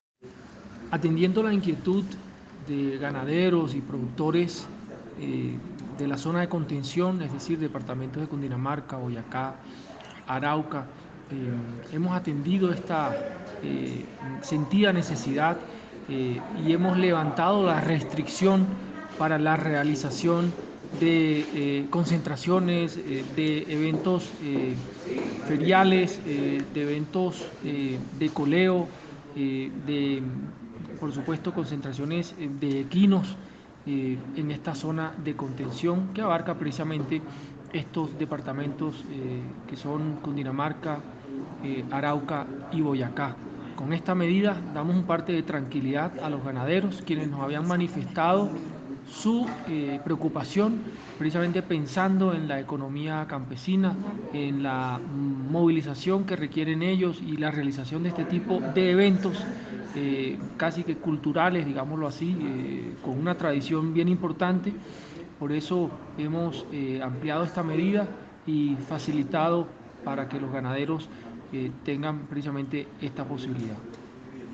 Declaraciones-gerente-ICA_1.mp3